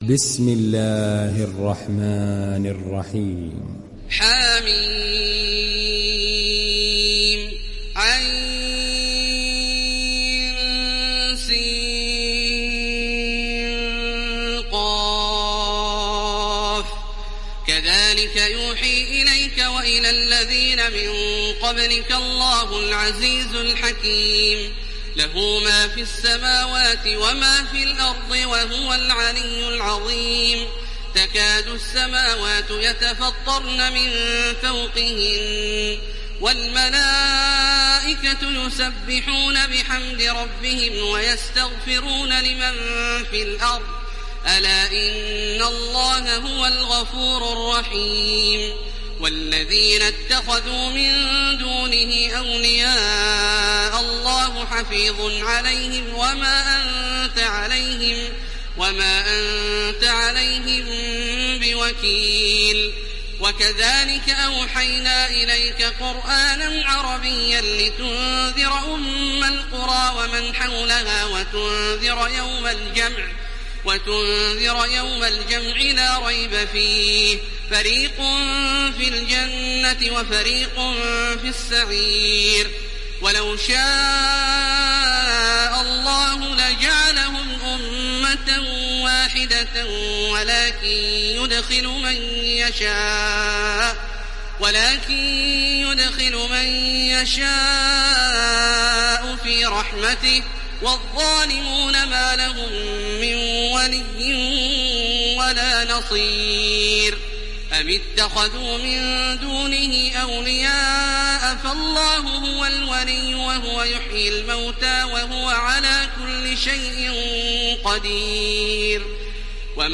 دانلود سوره الشورى تراويح الحرم المكي 1430